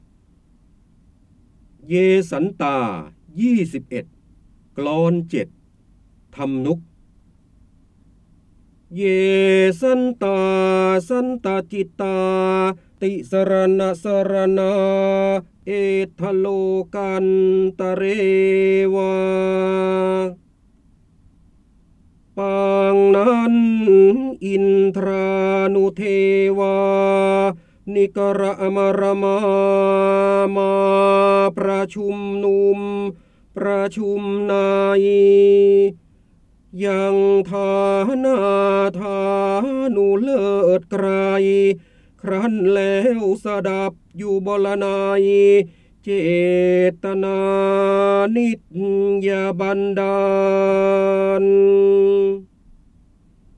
คำสำคัญ : พระเจ้าบรมโกศ, ร้อยแก้ว, พระโหราธิบดี, จินดามณี, ร้อยกรอง, การอ่านออกเสียง